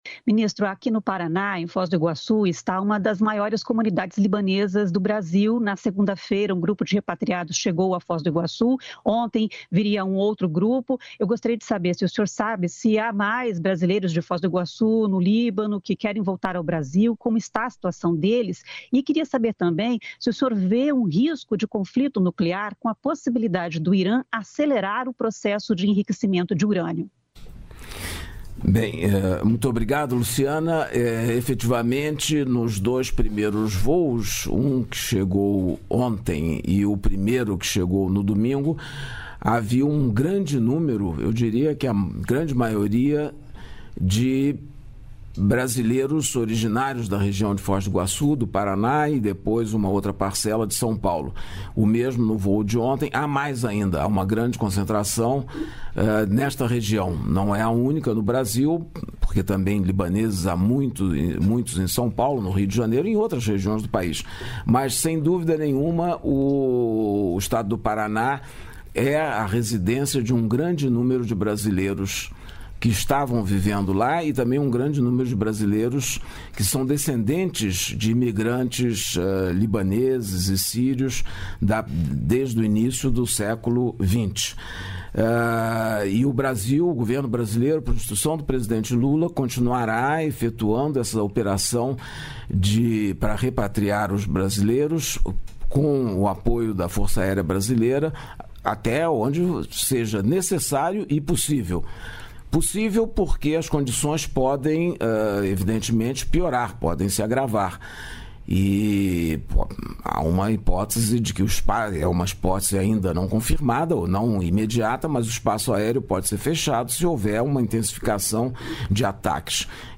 O entrevistado foi o ministro das Relações Exteriores, Mauro Vieira.
O ministro falou sobre diversos temas em resposta aos questionamentos de radialistas de oito emissoras do país.